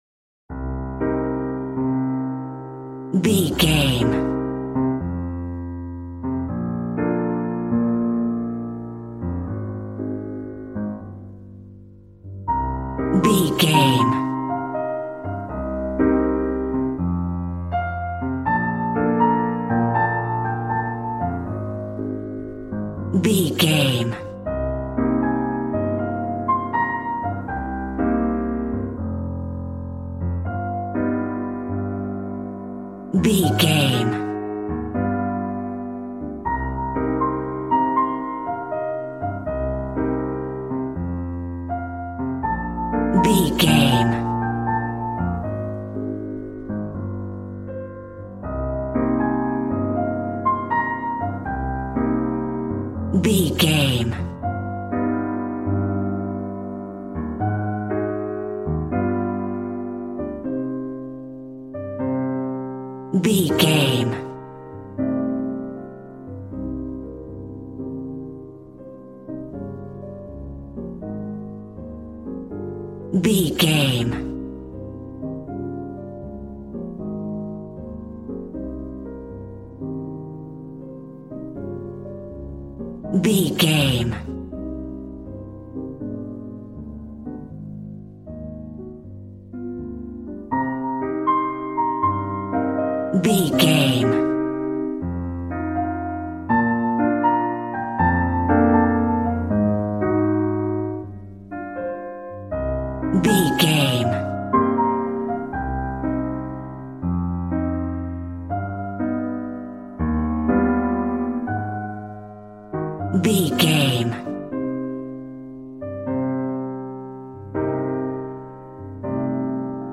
Smooth jazz piano mixed with jazz bass and cool jazz drums.,
Aeolian/Minor